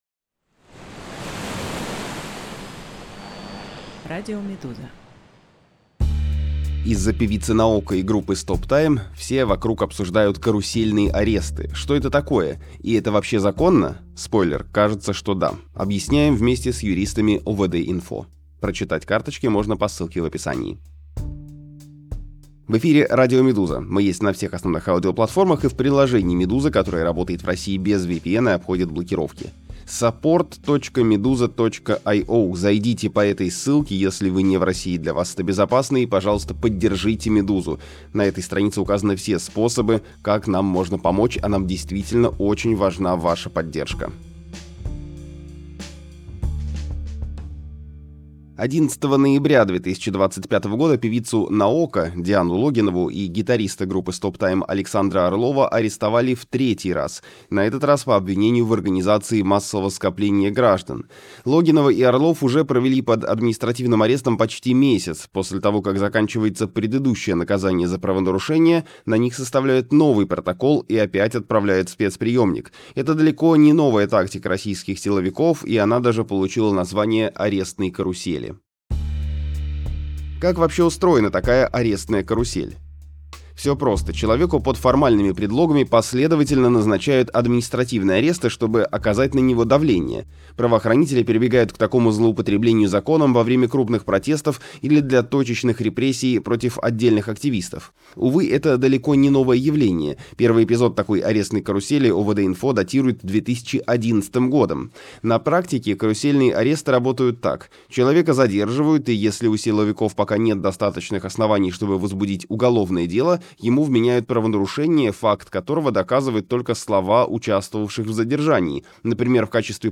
Аудиоверсии главных текстов «Медузы».